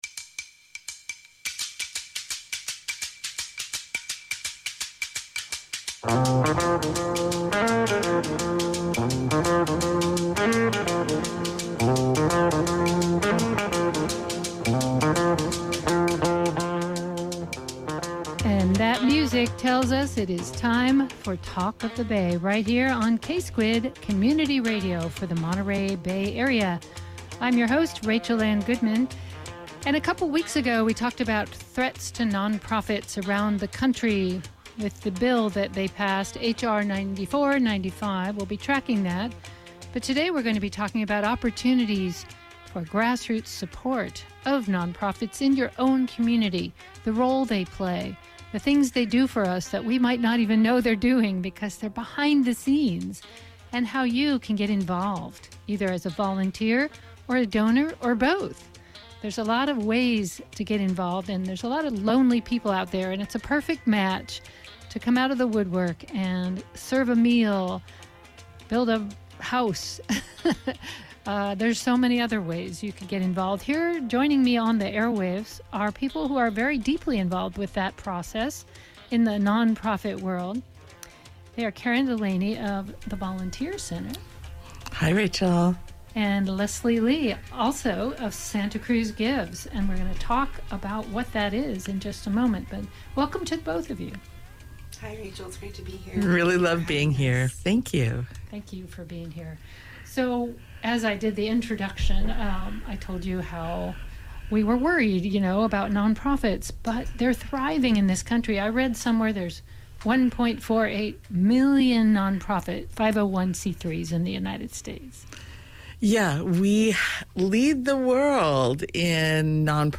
Santa-Cruz-Gives-interview24.mp3